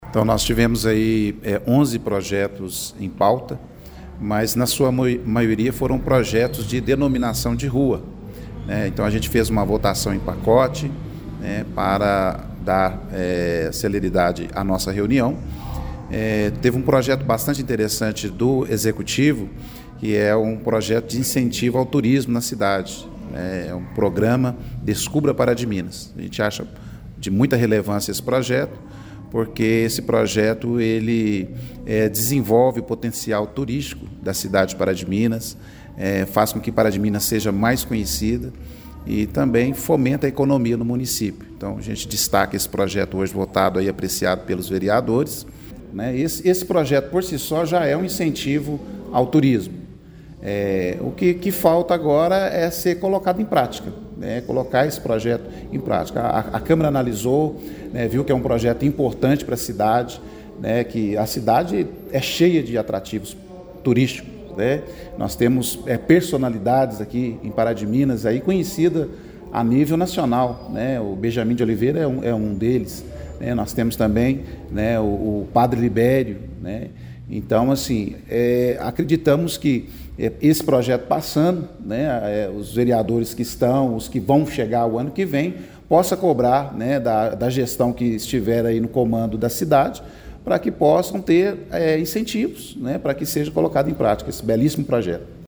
Ao comentar os outros temas, ele salientou que foi uma reunião tranquila com votação de projetos de denominação de ruas homenageando pessoas que fizeram muito pelo município e outro que pretende incentivar o turismo em Pará de Minas: